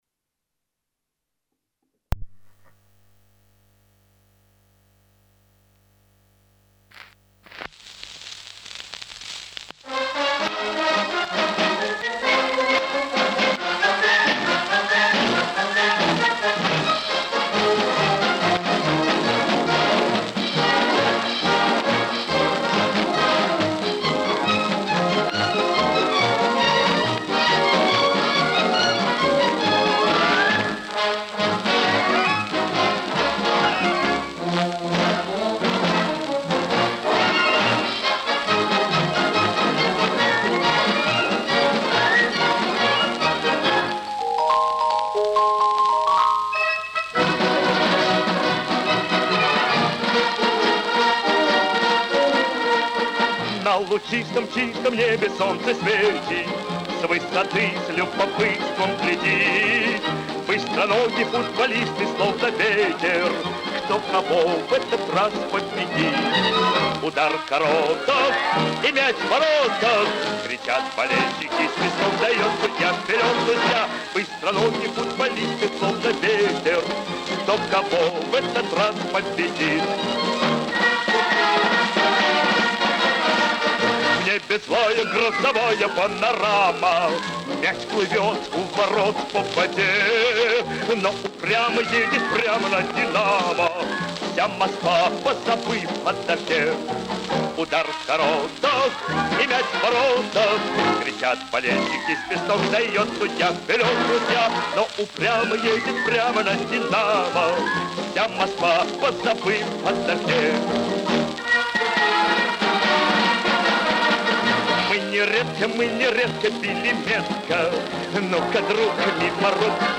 советский певец (баритон).